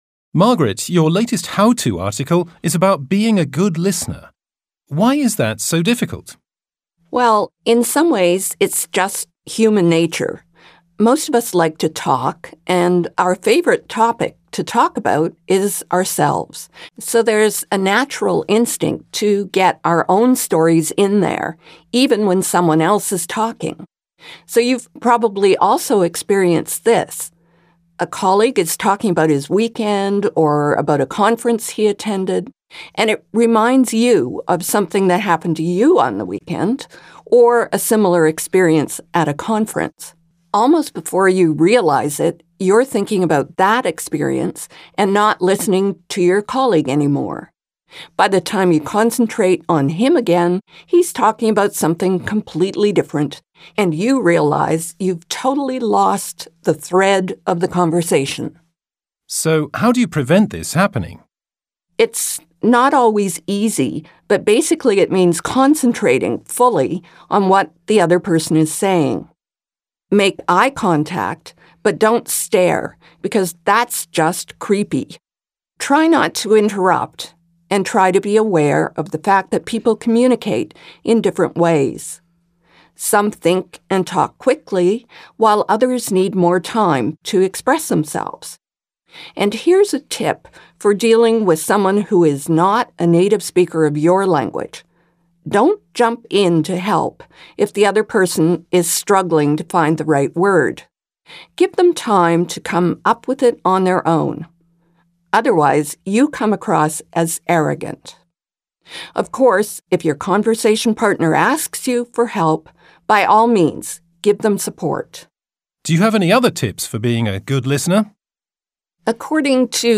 Careers & Management - Interview